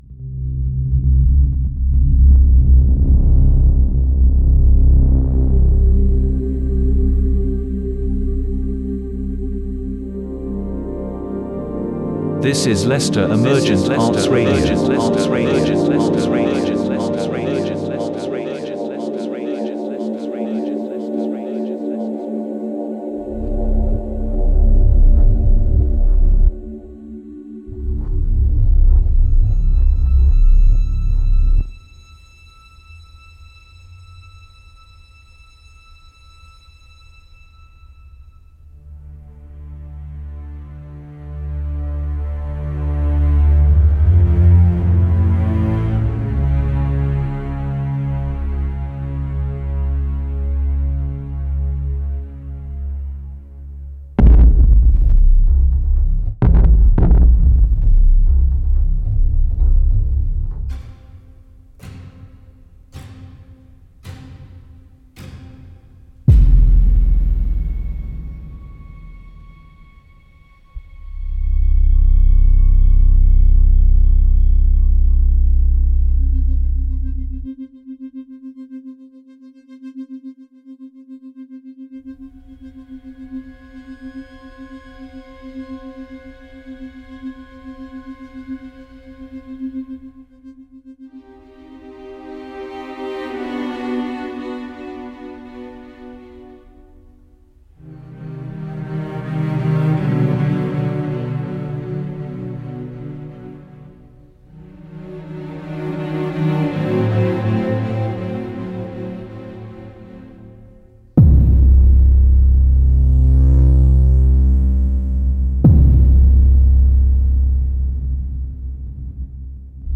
In episode 22 of the Distraction Therapy Podcast, listeners are invited to explore profound themes through a blend of poetry and music. This episode features the timeless words of classic poets paired with contemporary musical compositions, creating a rich and immersive auditory experience that delves into the interplay between light and darkness, both literally and metaphorically.